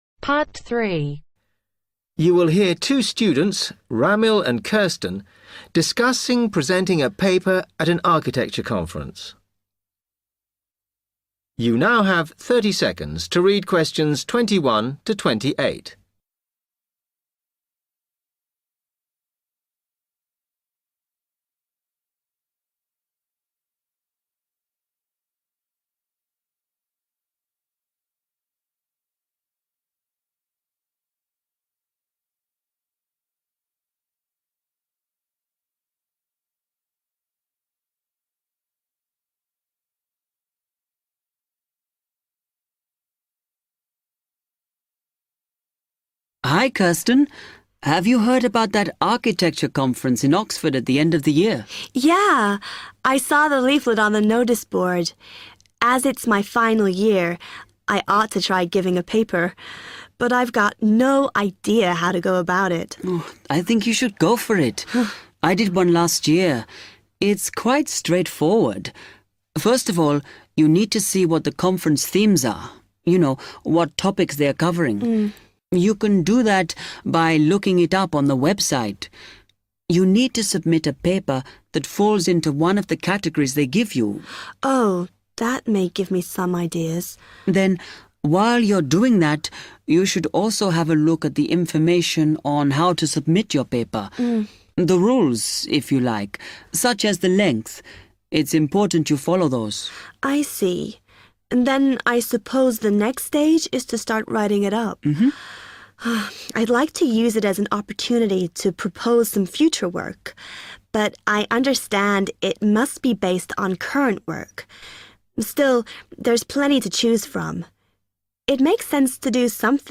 IELTSXPress-IELTS-Listening-Practice-Test-Section-3-To-submit-a-paper-for-the-Architecture-conference.mp3